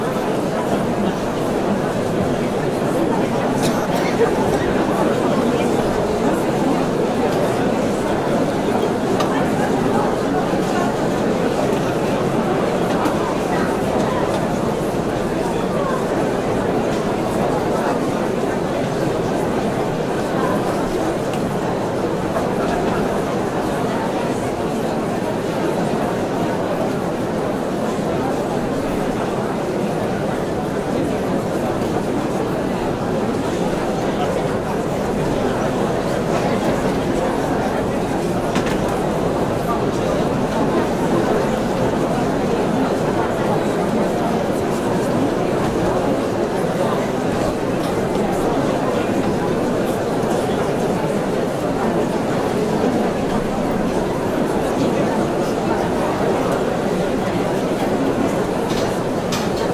crowd_talking_loop.ogg